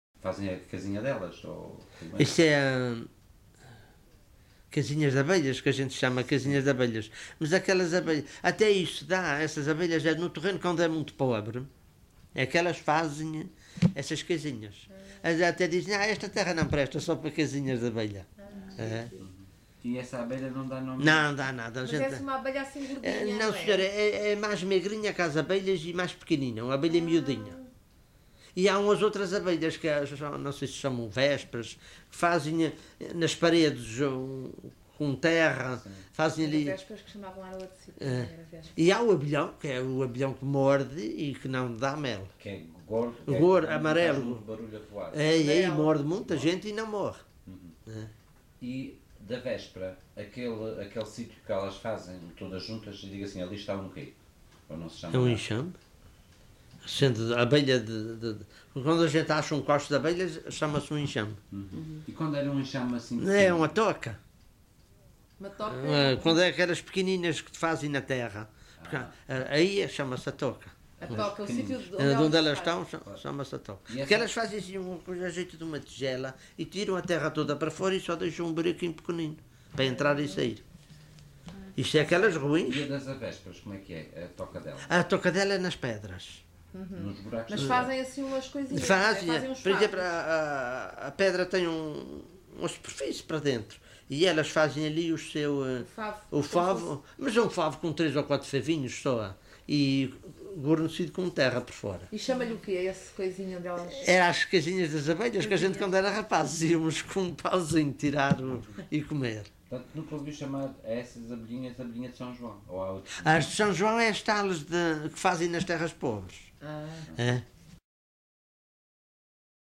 LocalidadePedras de São Pedro (Vila do Porto, Ponta Delgada)